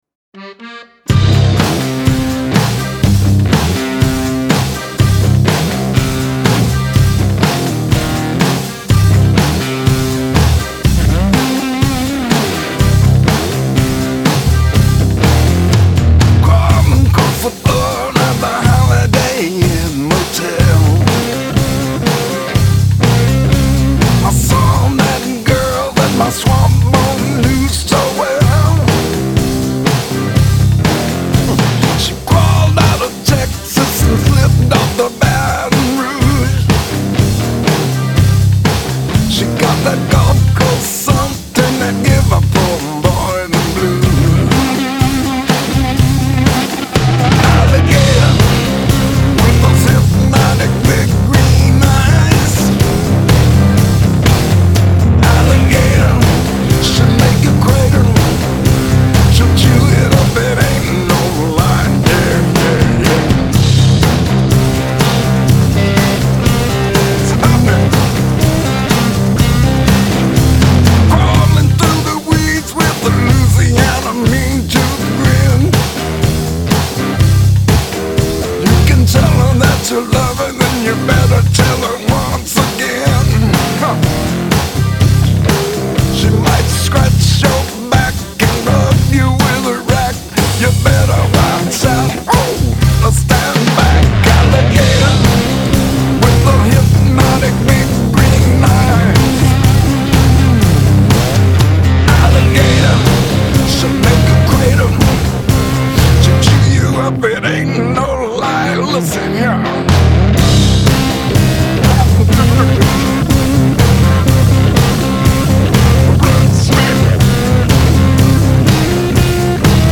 блюз
рок-музыка